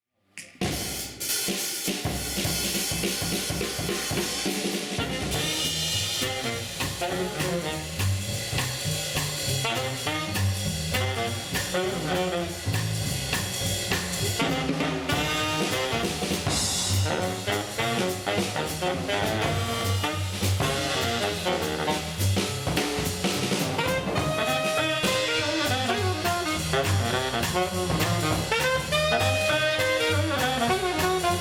saxophone alto
piano
saxophone baryton
contrebasse
batterie
chant.